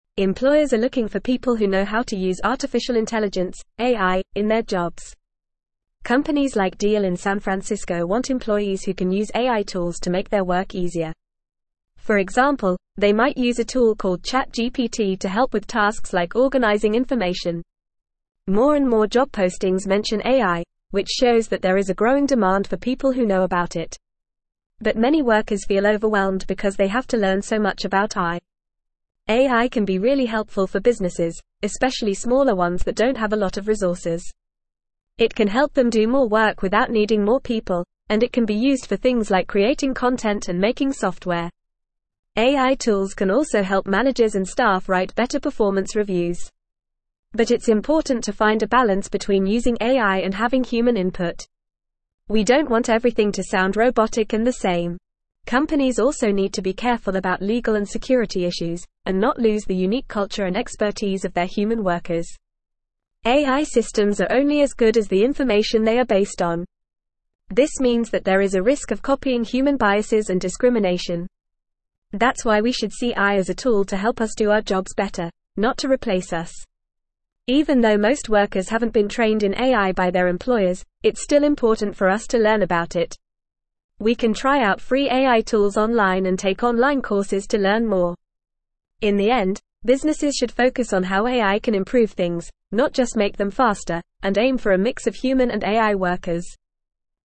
Fast
English-Newsroom-Upper-Intermediate-FAST-Reading-Employers-Seek-AI-Skills-as-Job-Posts-Double.mp3